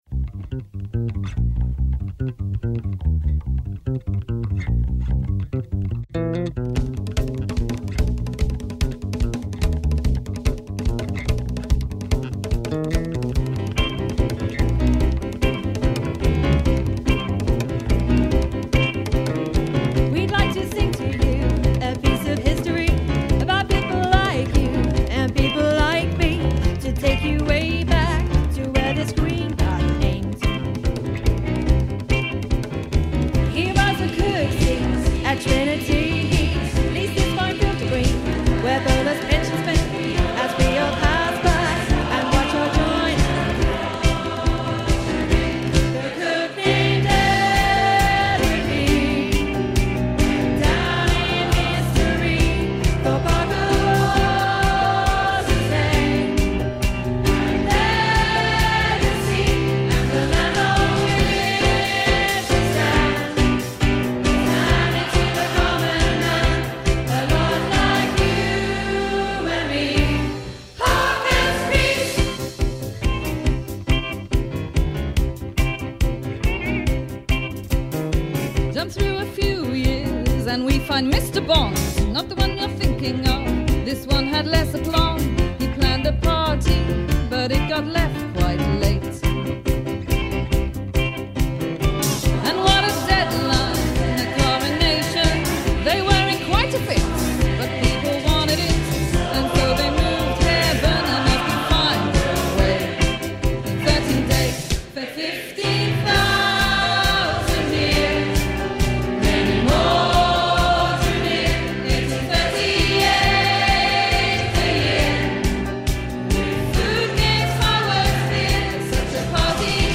Nine pieces are commissioned from a wide range of composers & poets, inspired by historical research & based using words from original sources of fascinating & quirky stories at iconic locations along the Tour's route in Cambridge. The songs will all be performed by local choirs, musicians and poets to make a free app for Cambridge to leave a lasting digital legacy.